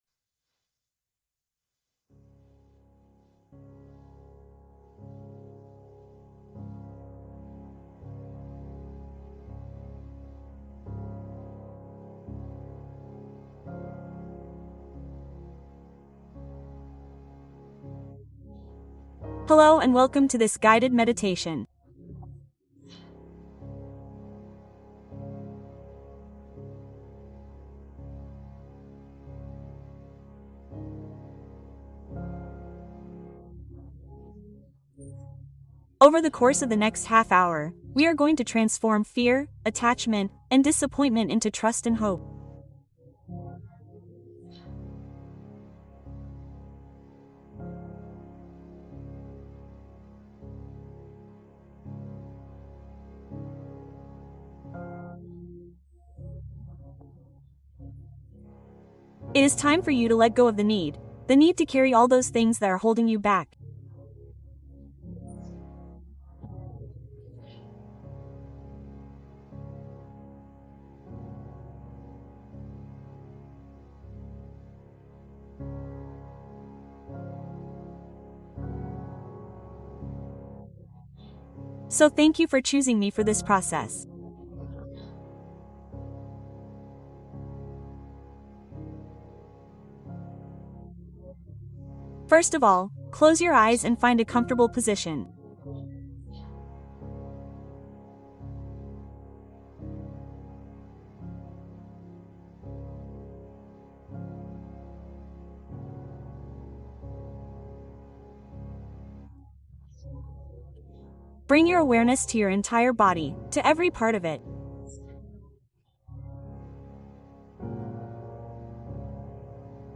Meditación para dejar ir a través de la renuncia consciente